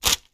Close Combat Break Bone 1.wav